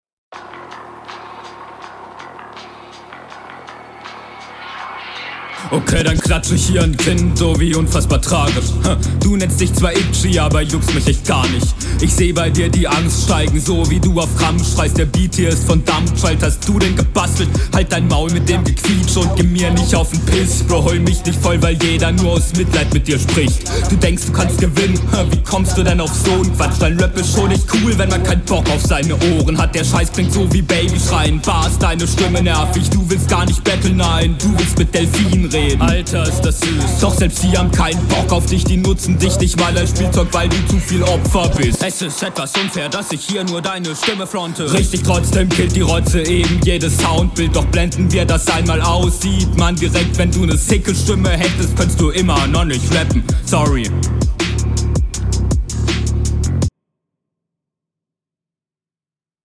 boah, richtig nice gerappt.